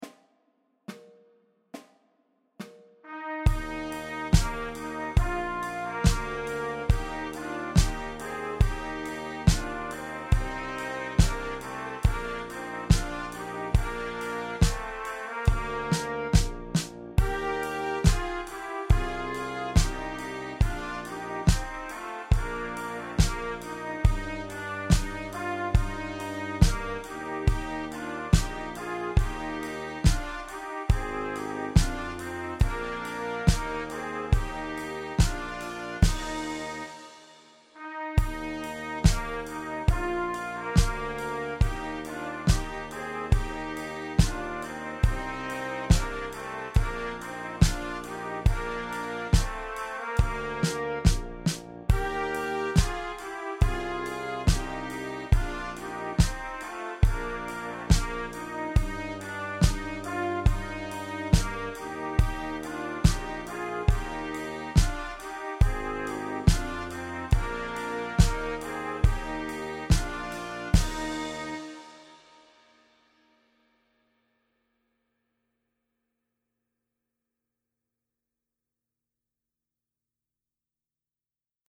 • 16 sehr leichte, dreistimmige Weihnachtslieder